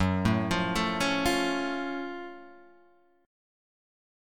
F# Minor Major 7th